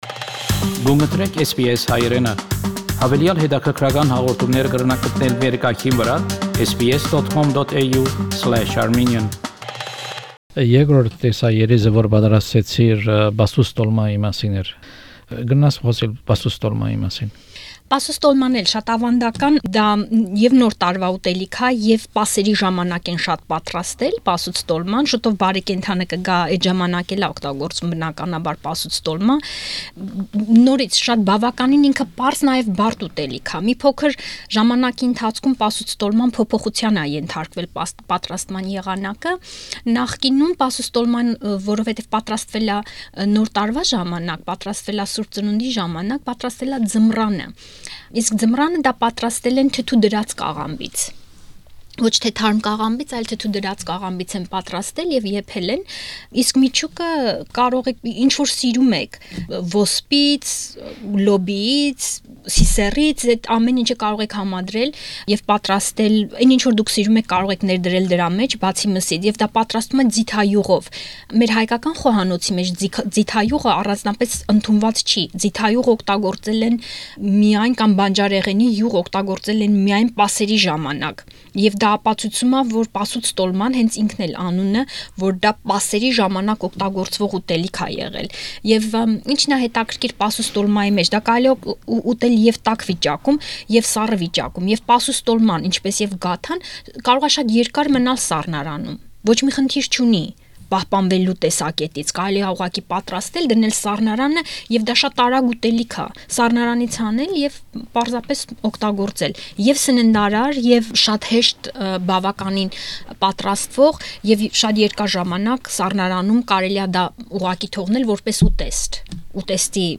MasterChef contestant